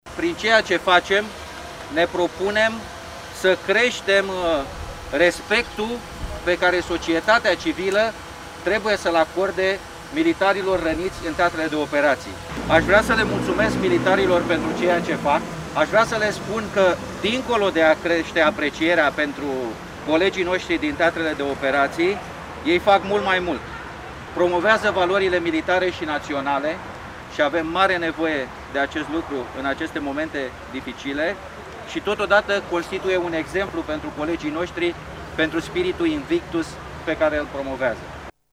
Vineri, în ziua startului la București a celei de-a VII-a ediții a ștafetei, șeful Statului Major al Apărării, general-locotenent Daniel Petrescu a declarat: